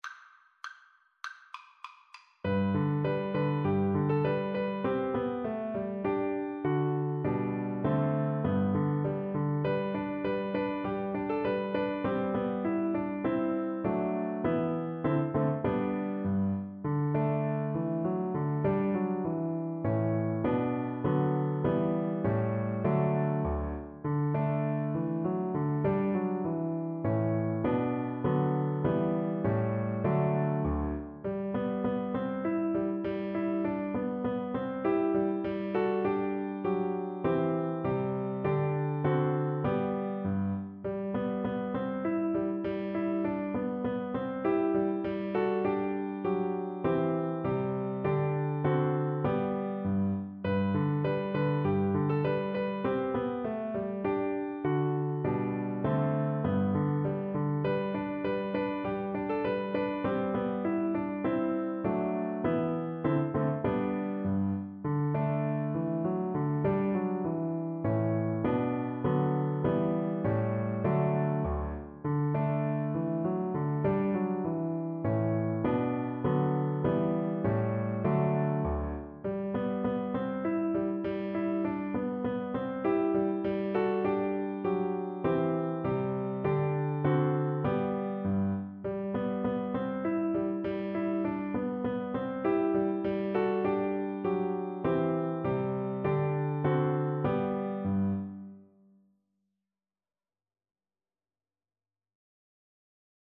Cello
Traditional Music of unknown author.
2/2 (View more 2/2 Music)
G major (Sounding Pitch) (View more G major Music for Cello )
Traditional (View more Traditional Cello Music)